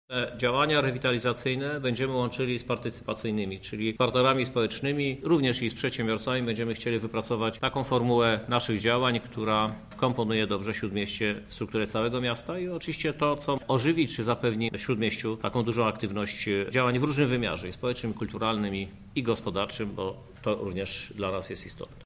Rewitalizacja Podzamcza -mówi prezydent miasta Lublin, Krzysztof Żuk.